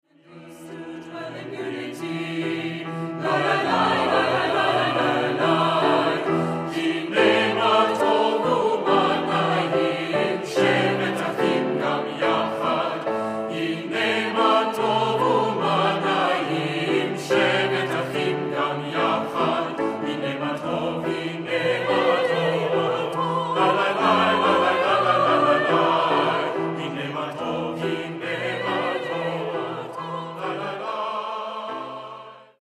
Accompaniment:      Piano
Music Category:      Choral
It has a strong 2/4 klezmer feel.